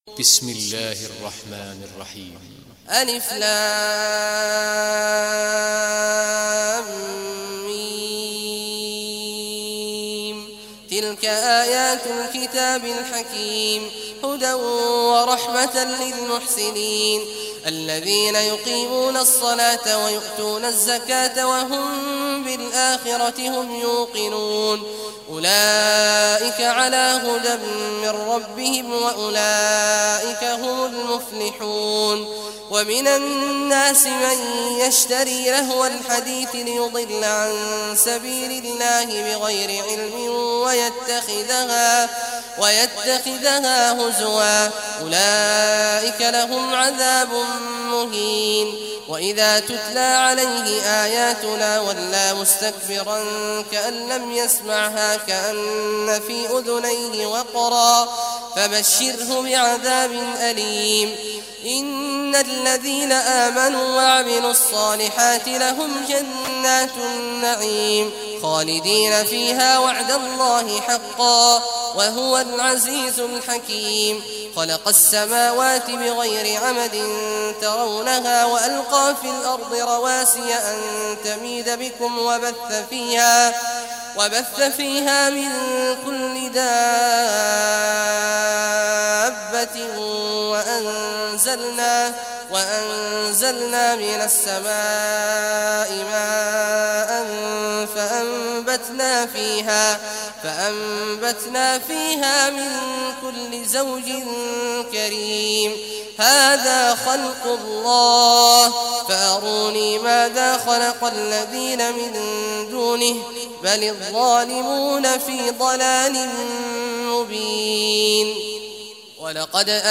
Surah Luqman Recitation by Sheikh Awad Juhany
Surah Luqman, listen or play online mp3 tilawat / recitation in the beautiful voice of Sheikh Abdullah Awad al Juhany.